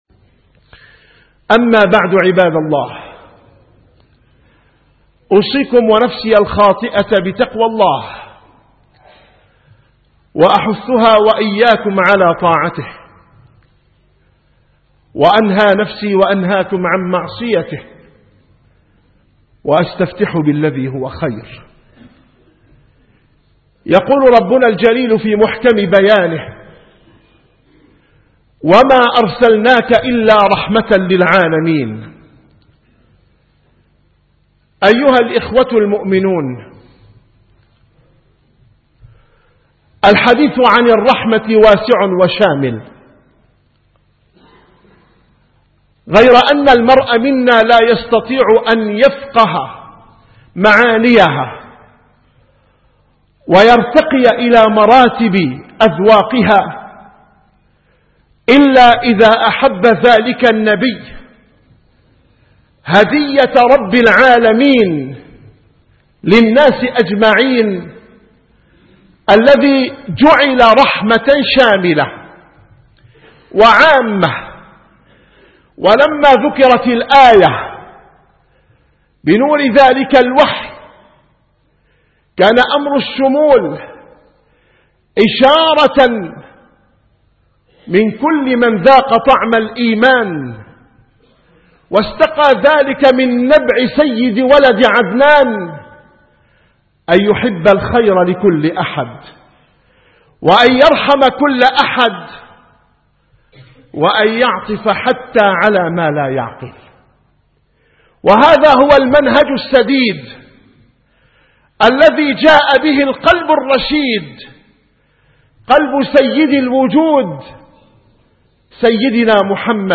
- الخطب